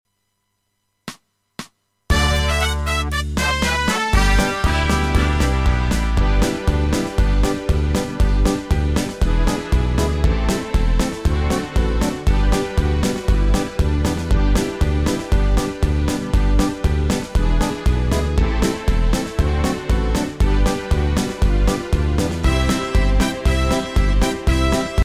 Rubrika: Národní, lidové, dechovka
- směs - polka
úvod 2 takty (4/4)
G dur